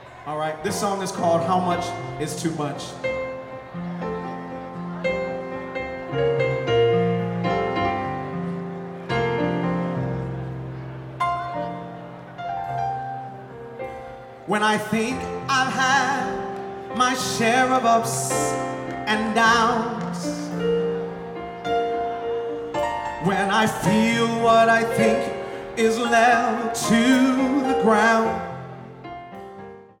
New Orleans, LA - May 1, 2014 Gospel Tent